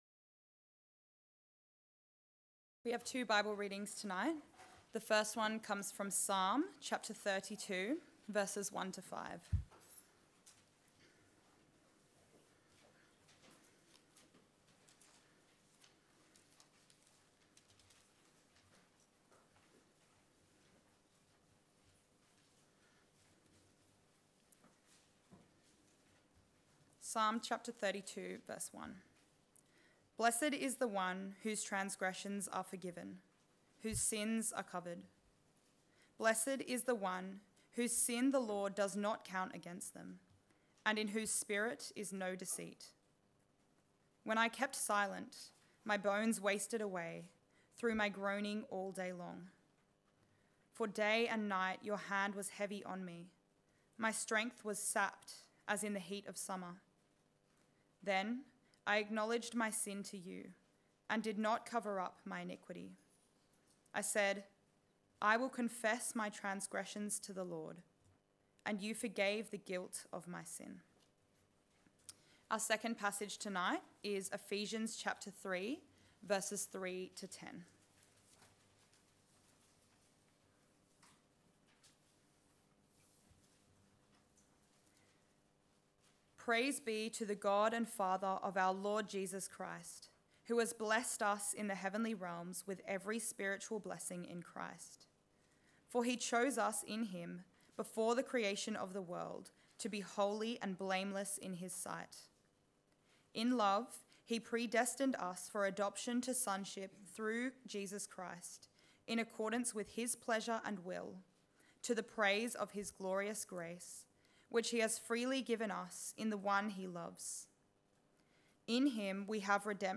Sermon: In the Forgiveness of Sins